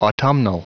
Prononciation du mot autumnal en anglais (fichier audio)
Prononciation du mot : autumnal